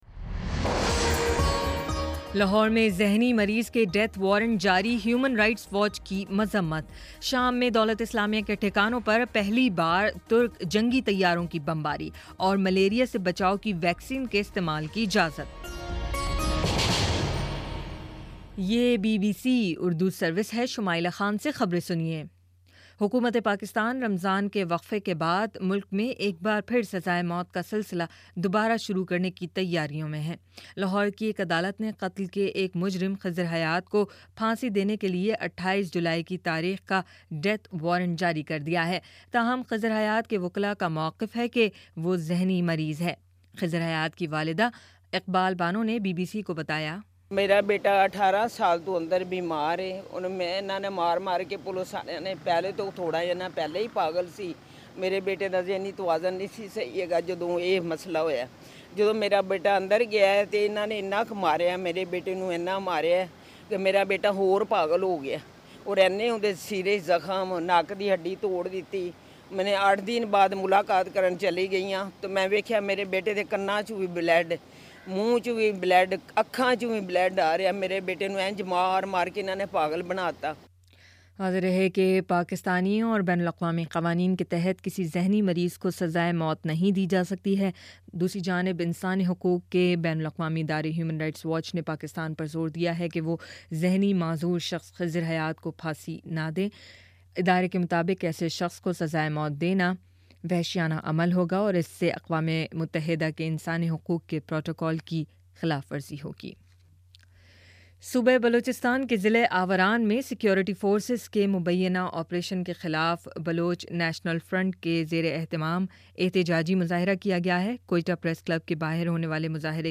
جولائی 24: شام پانچ بجے کا نیوز بُلیٹن